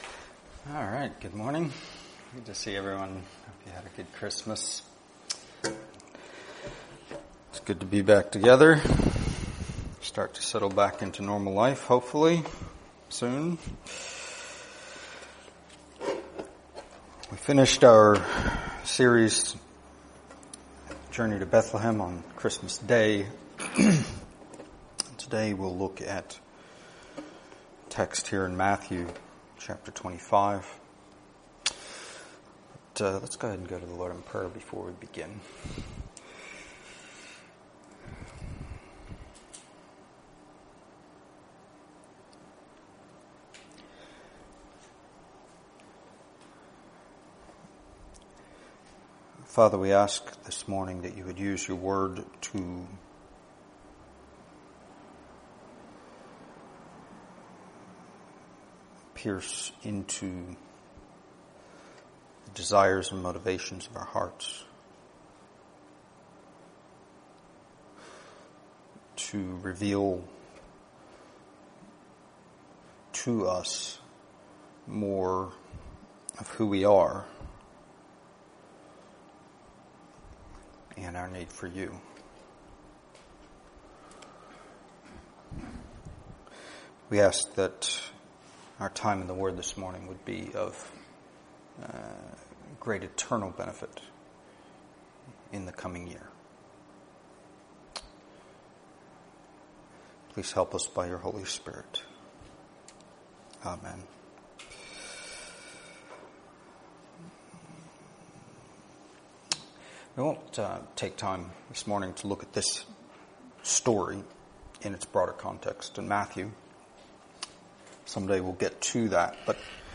Series: Stand Alone Sermons